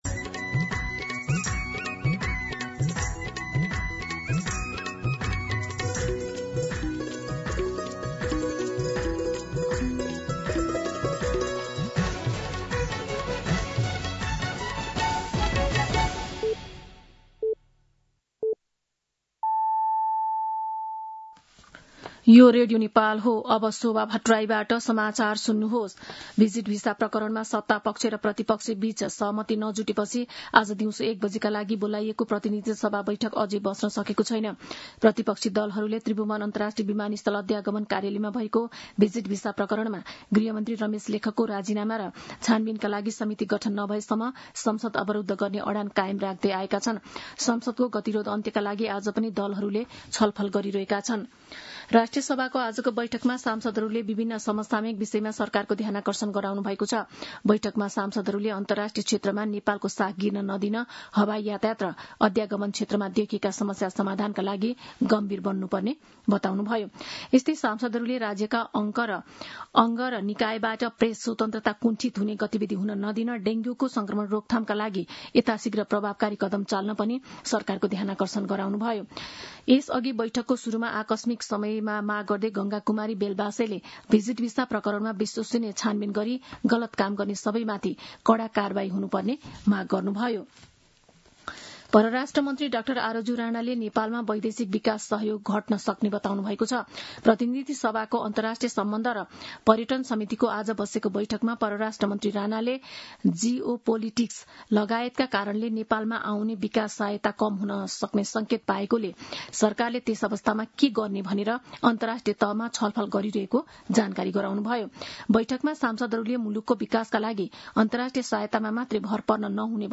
दिउँसो ४ बजेको नेपाली समाचार : ३० जेठ , २०८२